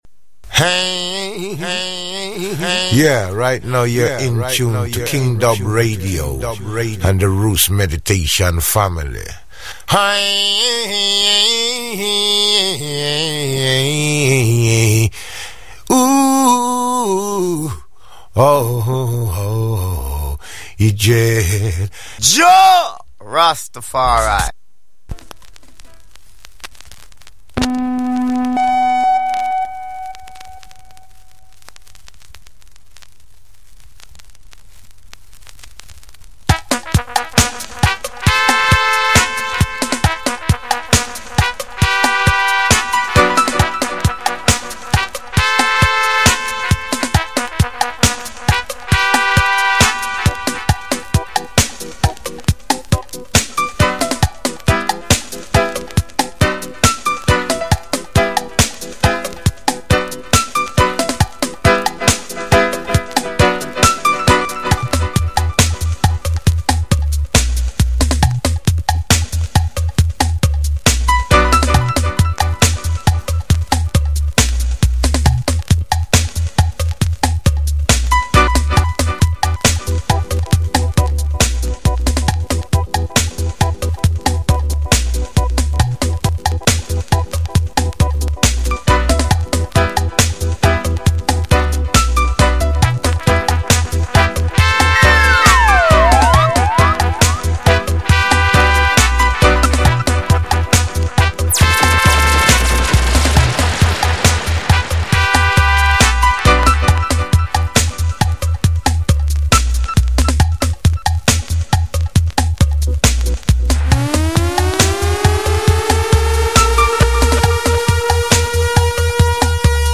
STRICTLY ROOTS & CULTURAL SELECTION !!!